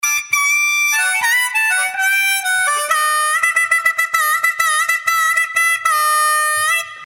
描述：起步缓慢
标签： 60 bpm Blues Loops Harmonica Loops 1.35 MB wav Key : Unknown
声道立体声